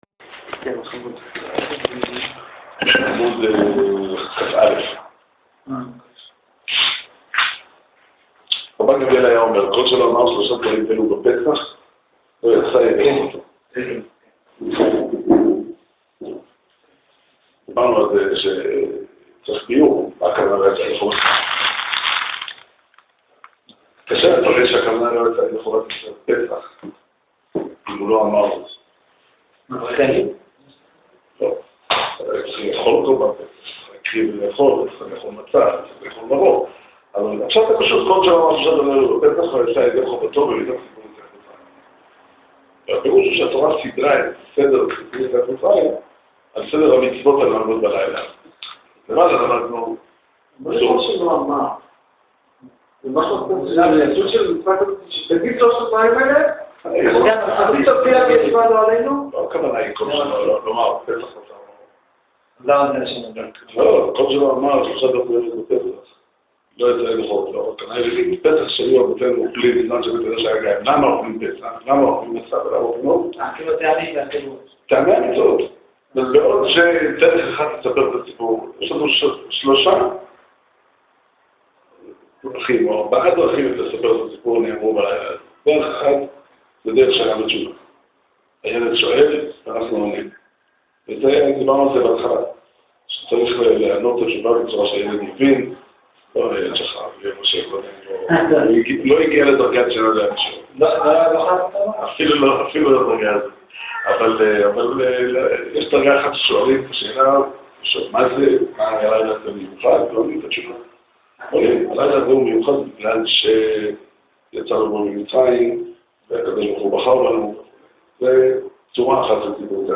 שיעור שנמסר בבית המדרש 'פתחי עולם' בתאריך ד' ניסן תשע"ח